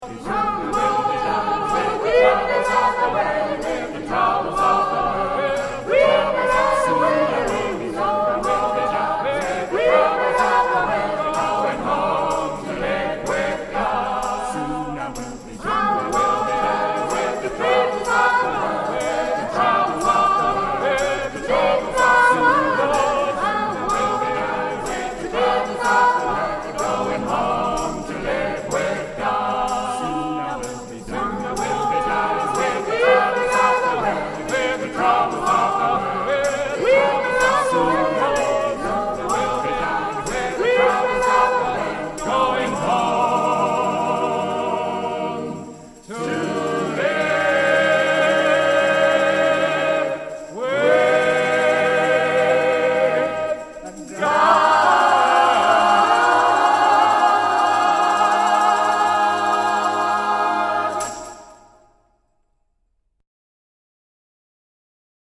Traditional Spiritual.